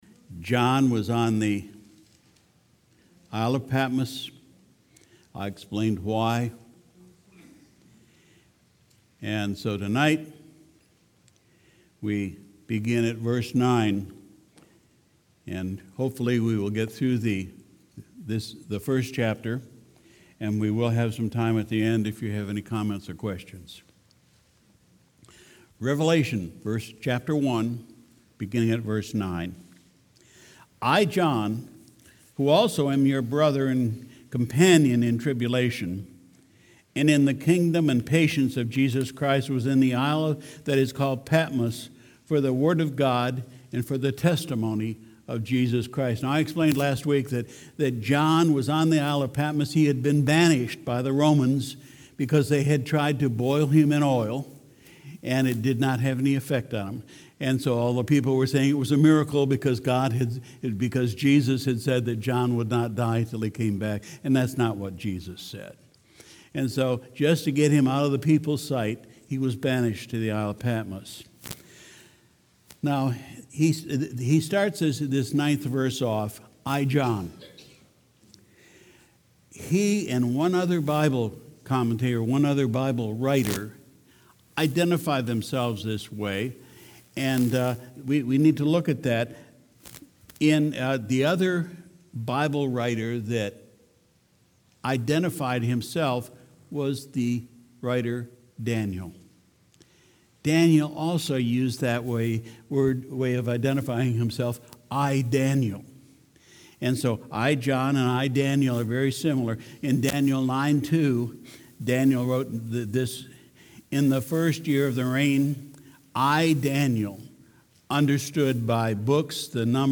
Sunday, September 1, 2019 – Evening Service